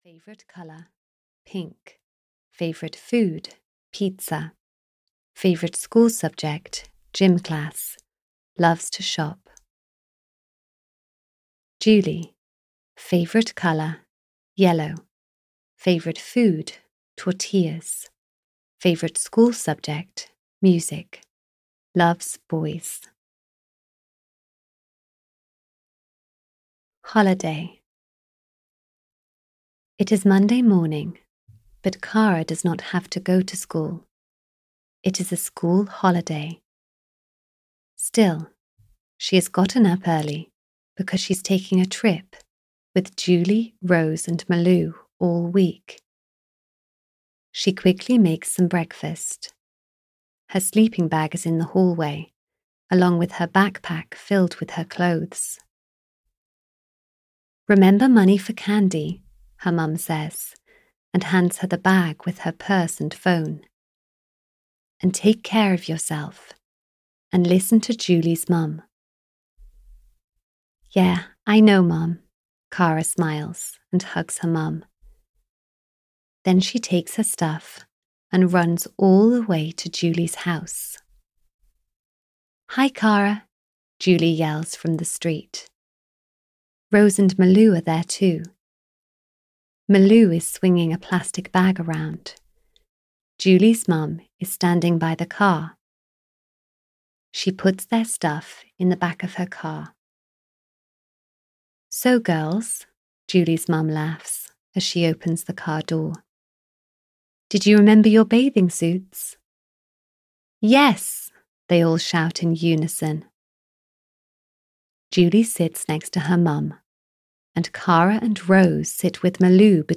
K for Kara 19 - Are You in Love? (EN) audiokniha
Ukázka z knihy